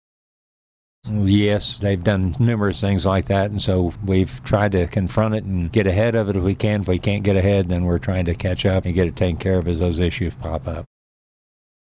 2. Senator Cunningham adds he believes this bill to be necessary because of the Missouri Department of Revenue.